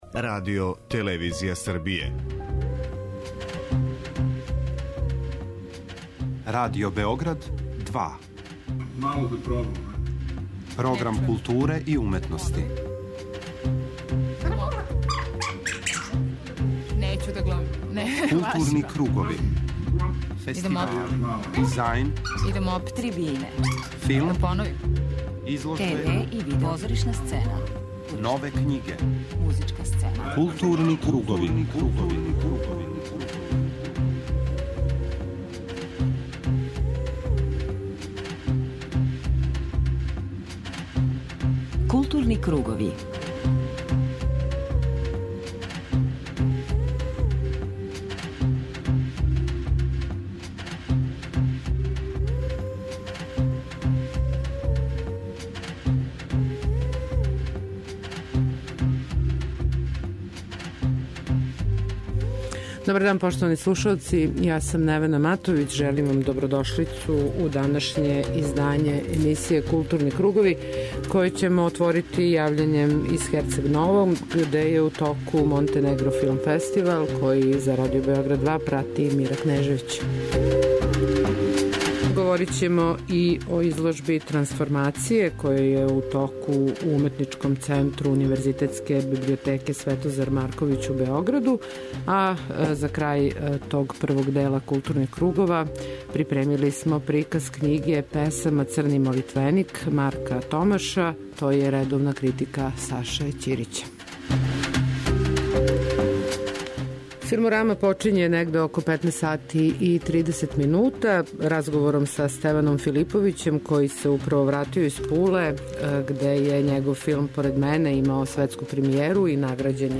преузми : 53.15 MB Културни кругови Autor: Група аутора Централна културно-уметничка емисија Радио Београда 2.